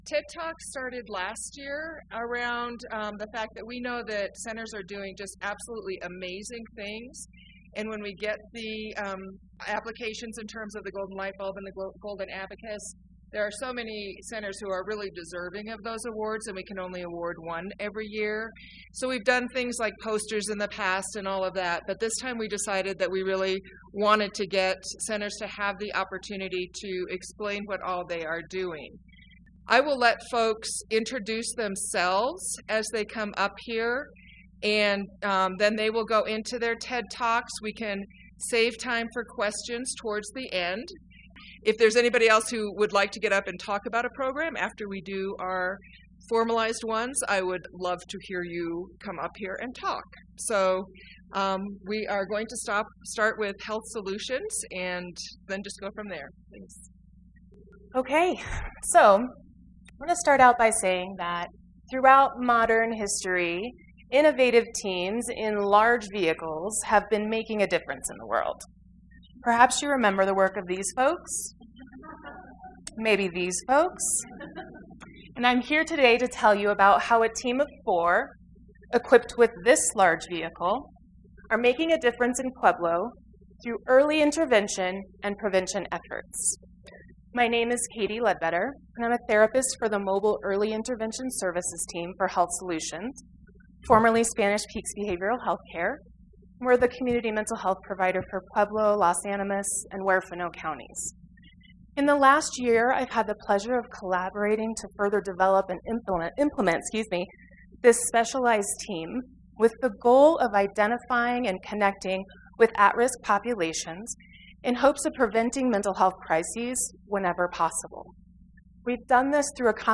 If you missed them at the conference or what to hear them again; re-visit the 4 talks presented by the 2015 Golden Lightbulb and Golden Abacus award nominees.